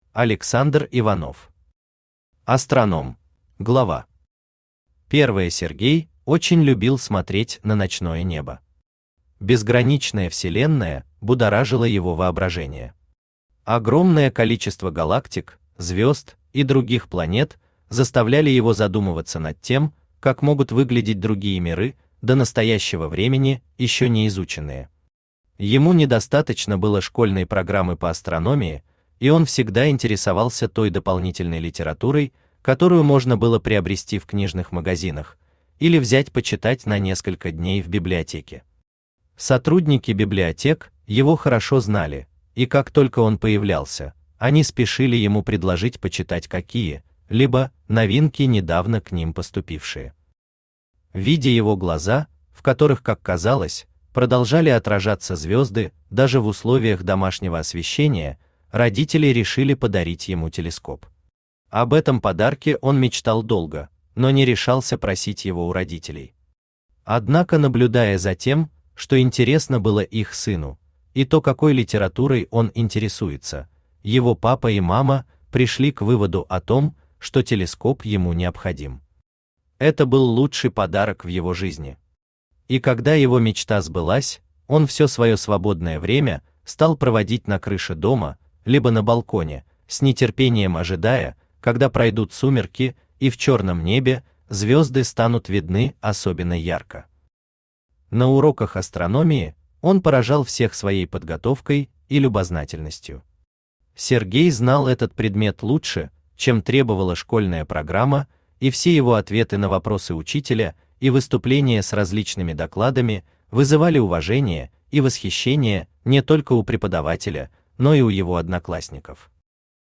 Аудиокнига Астроном | Библиотека аудиокниг
Aудиокнига Астроном Автор Александр Иванович Иванов Читает аудиокнигу Авточтец ЛитРес.